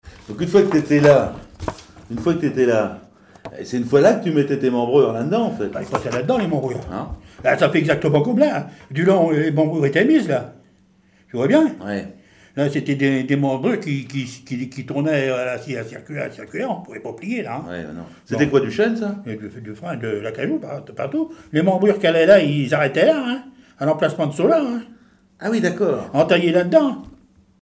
Témoignages d'un charpentier naval sur les techniques locales
Catégorie Témoignage